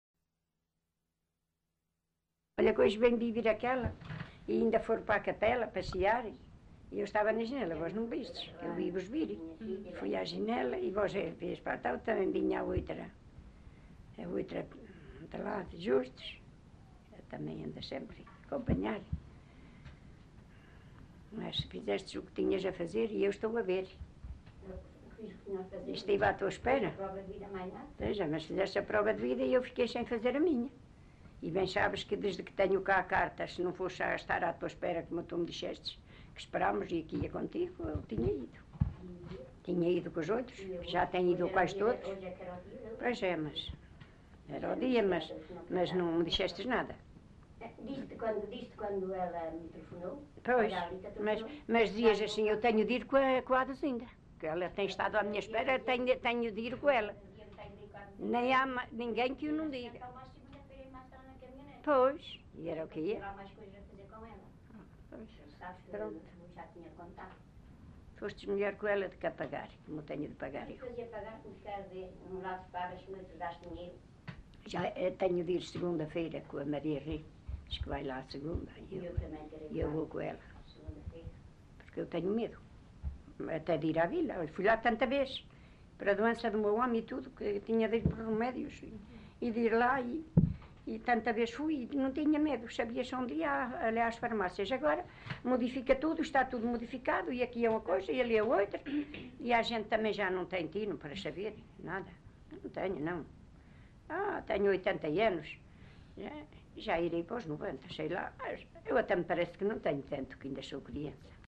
LocalidadePerafita (Alijó, Vila Real)